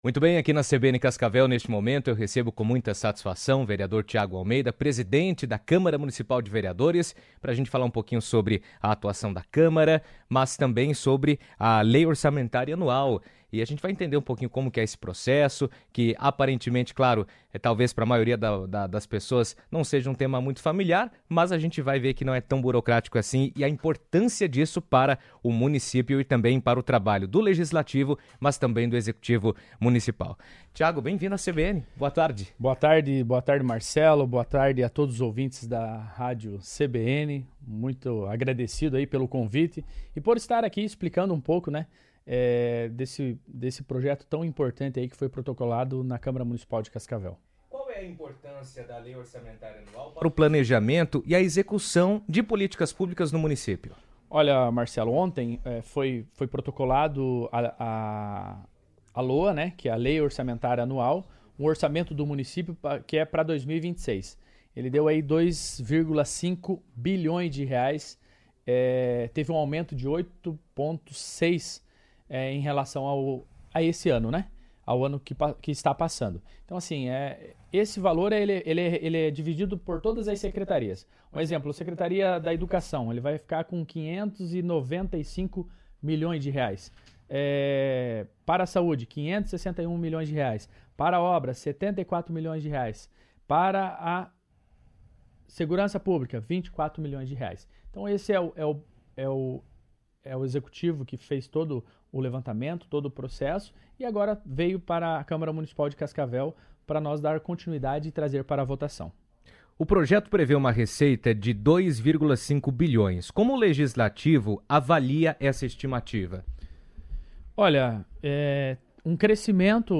A Prefeitura de Cascavel protocolou nesta quarta-feira (12) o Projeto de Lei Orçamentária Anual (LOA) de 2026, documento que consolida as estimativas de receita e fixa as despesas do município para o próximo ano. A proposta, foi entregue ao presidente da Câmara, vereador Tiago Almeida (Republicanos), que destacou a importância da etapa e comentou os próximos passos de tramitação durante entrevista à rádio CBN.